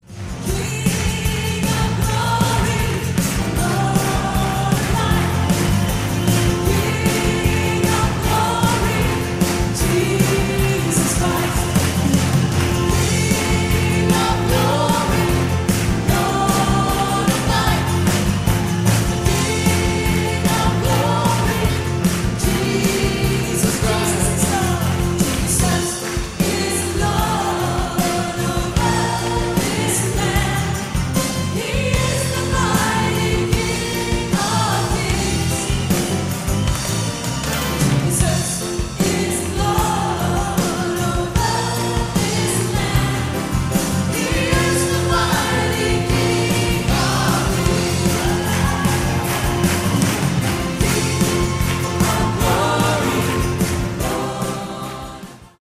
Recorded live at Goodnews Crusade across 2 camp meetings.